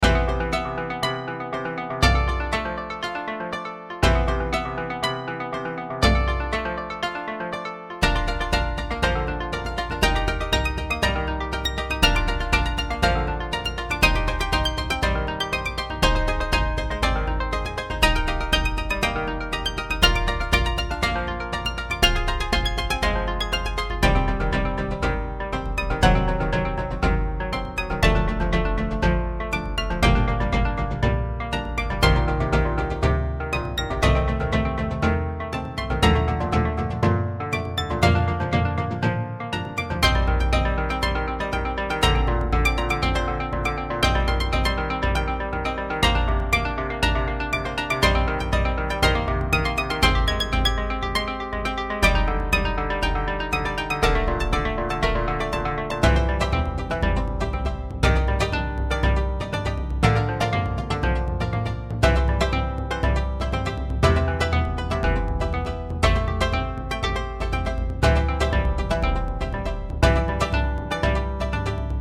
三味線、琴、太鼓 ※和楽器